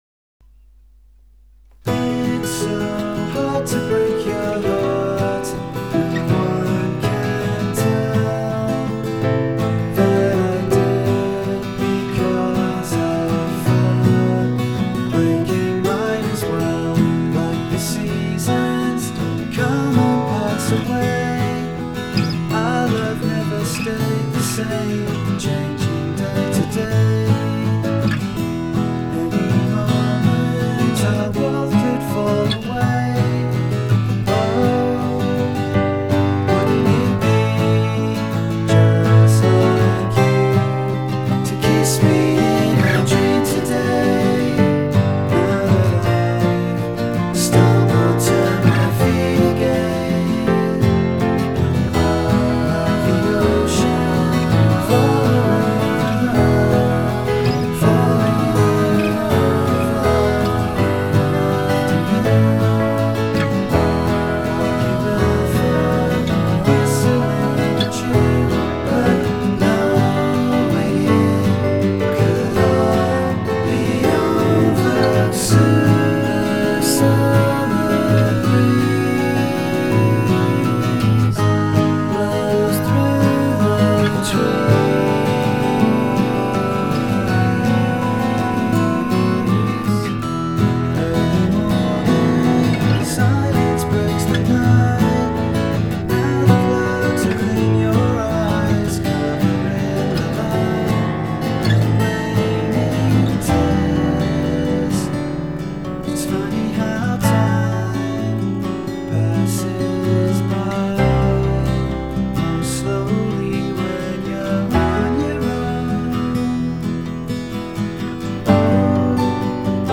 * Demo *